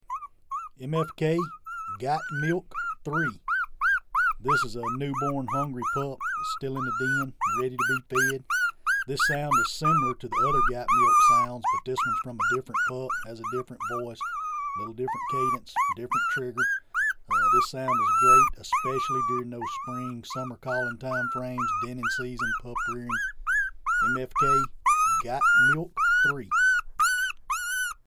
Recorded with the best professional grade audio equipment MFK strives to produce the highest
The Big Difference- Our one-of-a-kind live coyote library naturally recorded at extremely close
range from our very own hand raised, free range coyotes sets MFK apart from all other libraries.